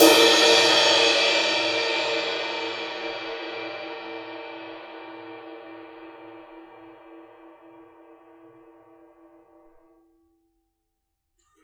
susCymb1-hitstick_fff_rr1.wav